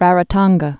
(rărə-tŏnggə)